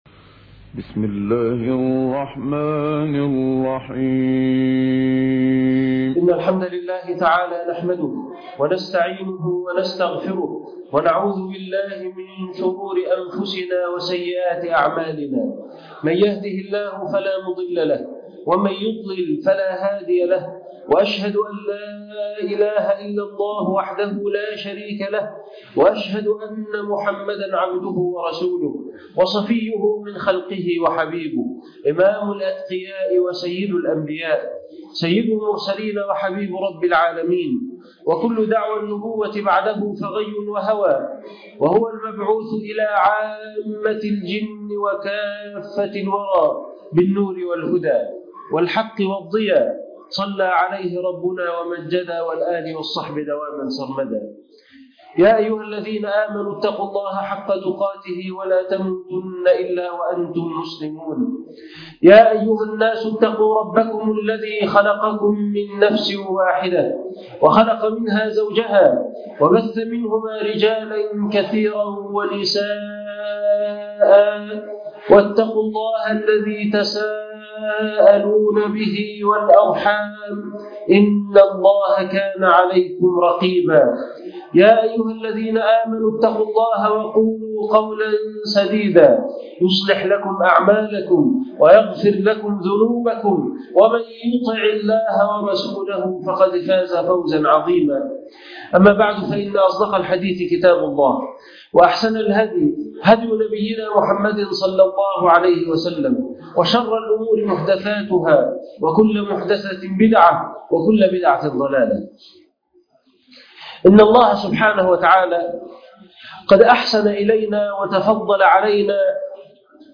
الاستعداد بالأعمال الصالحة لرمضان خطبة جمعة